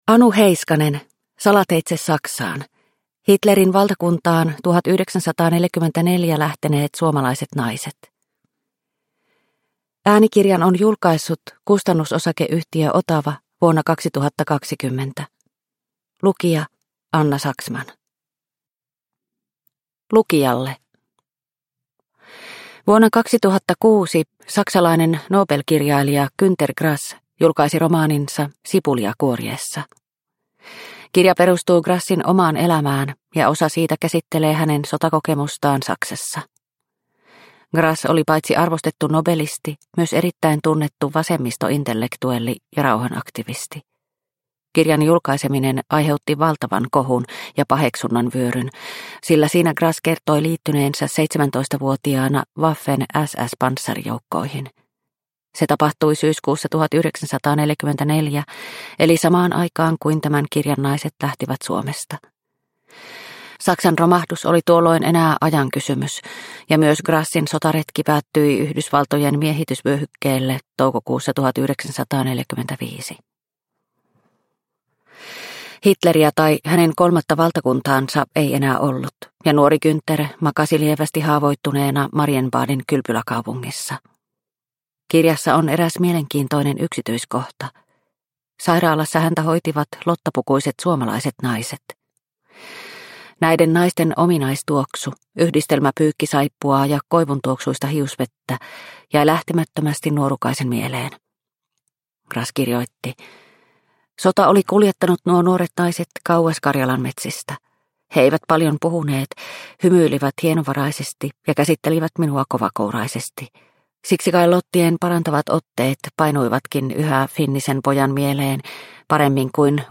Salateitse Saksaan – Ljudbok – Laddas ner